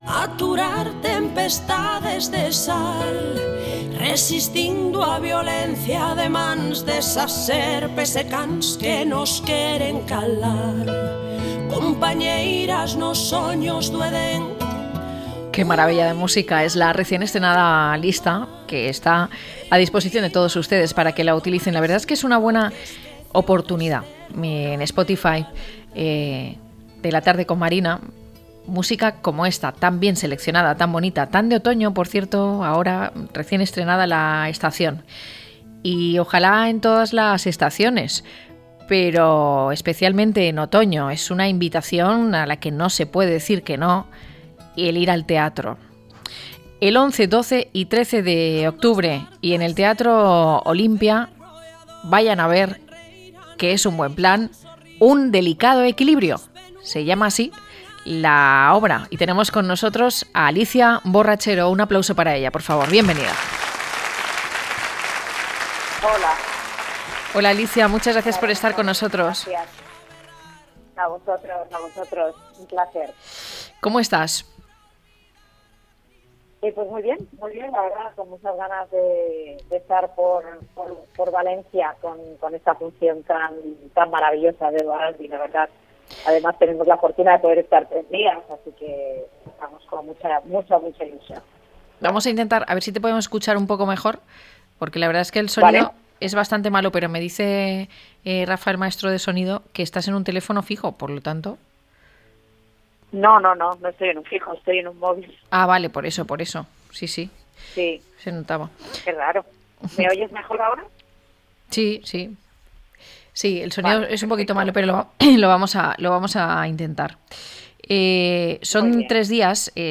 Hablamos con una de sus protagonistas, Alicia Borrachero, para entender el trasfondo de esta obra y los detalles más interesantes.